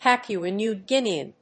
アクセント・音節Pápua Nèw Guín・e・an